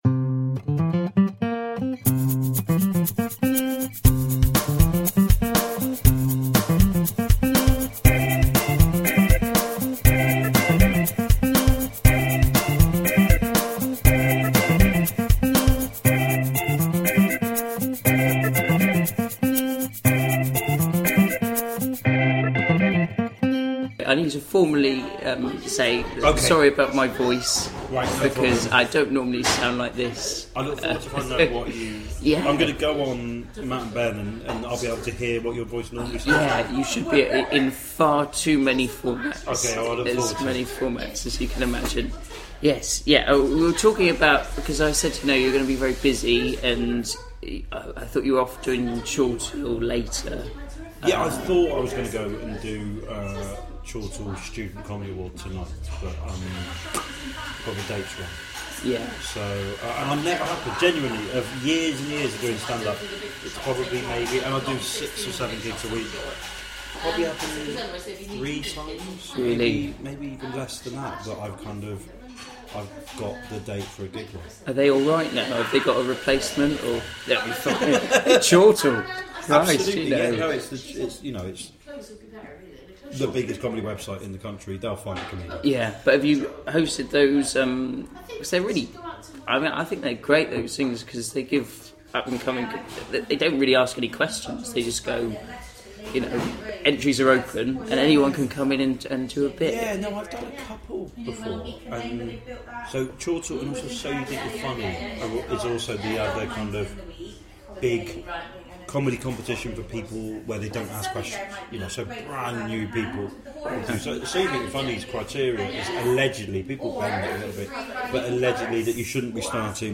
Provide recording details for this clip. Coffee Shop Interview